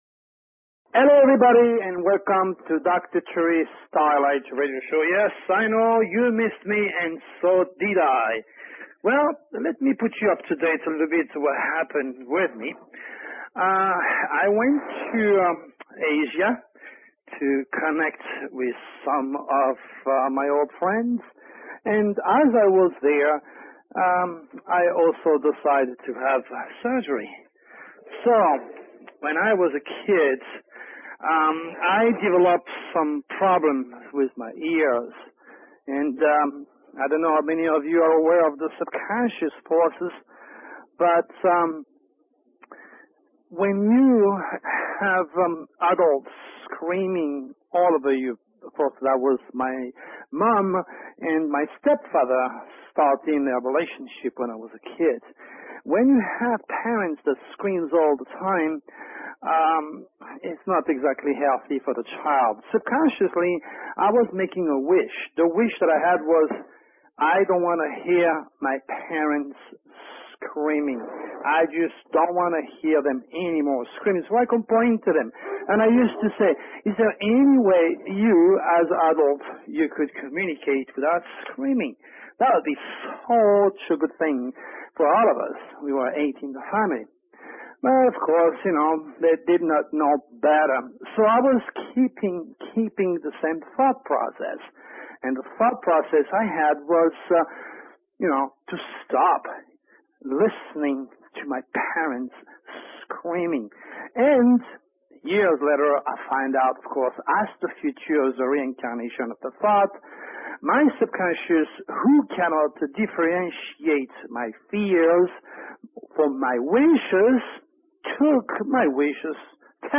Talk Show Episode, Audio Podcast, Starlight_Radio and Courtesy of BBS Radio on , show guests , about , categorized as